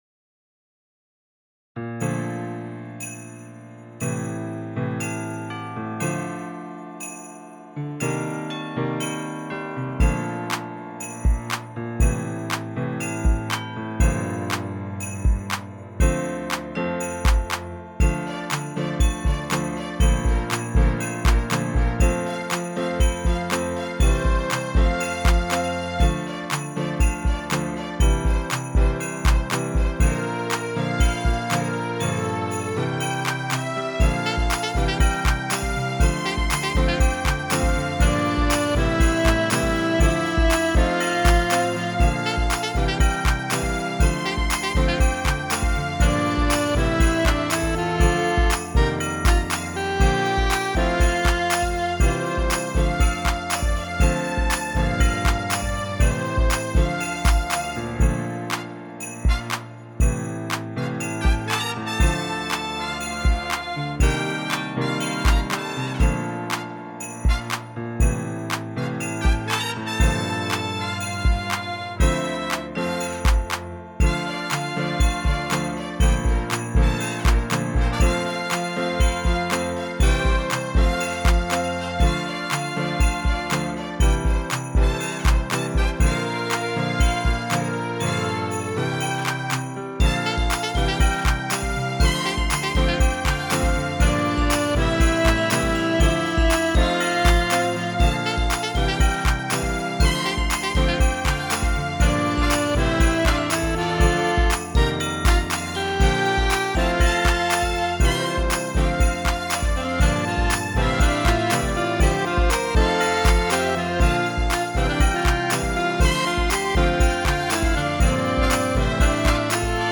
インスト音源 / コード譜 配布